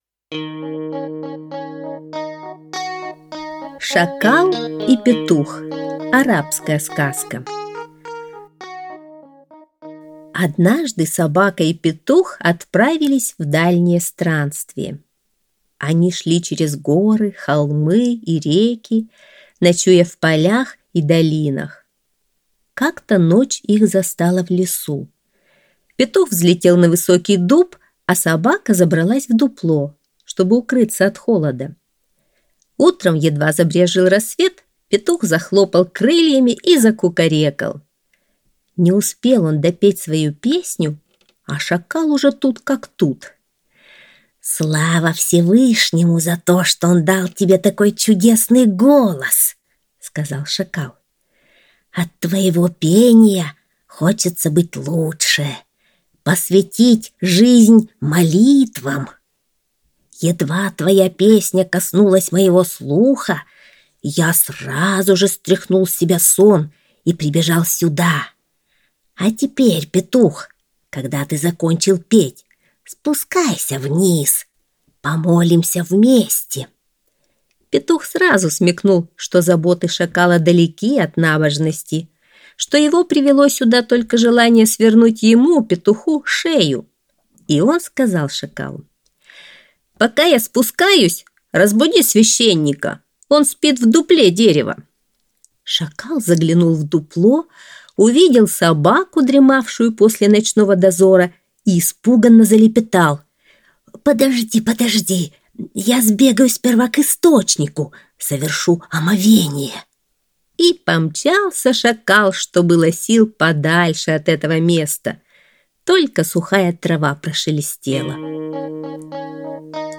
Шакал и Петух – арабская аудиосказка